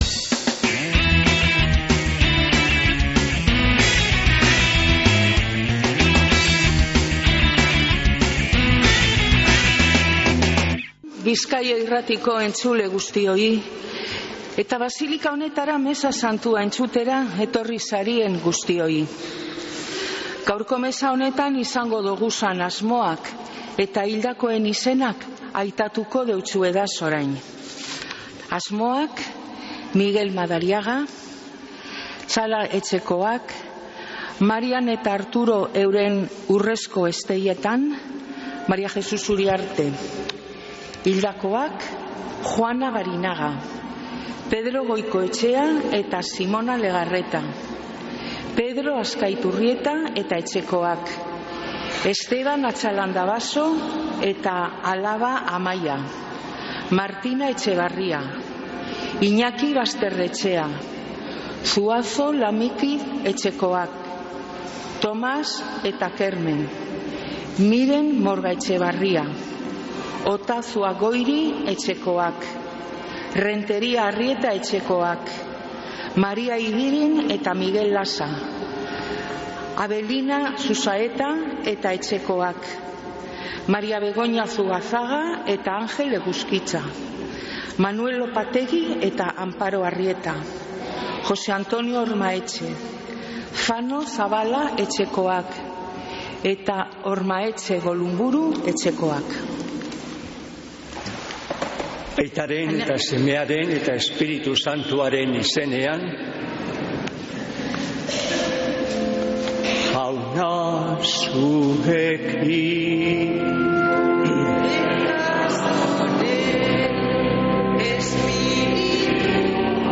Mezea Begoñatik Begoñako Ama Birjiñaren egunean | Bizkaia Irratia